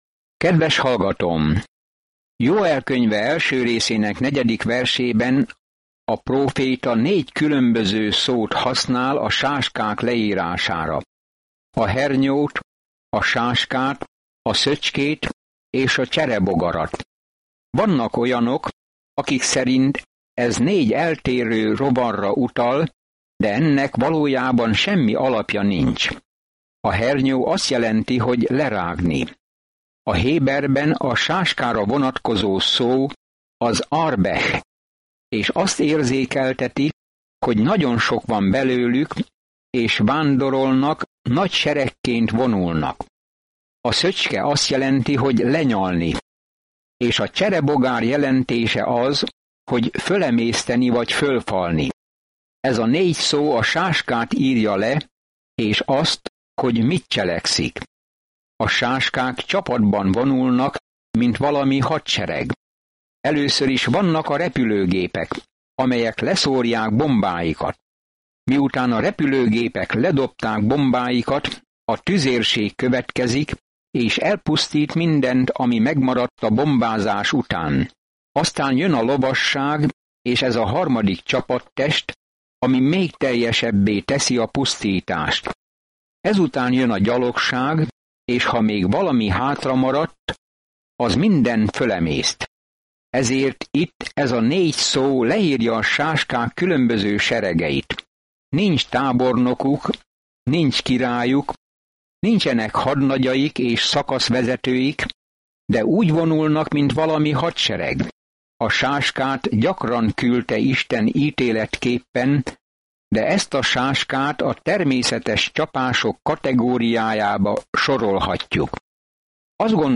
Szentírás Jóel 1:5-15 Nap 1 Olvasóterv elkezdése Nap 3 A tervről Isten sáskák csapását küldi, hogy ítéljék Izraelt, de ítélete mögött egy prófétai jövendőbeli „Úr napjának” leírása áll, amikor Isten végre kimondja a szavát. Napi utazás Joelen keresztül, miközben hallgatod a hangos tanulmányt, és olvasol válogatott verseket Isten szavából.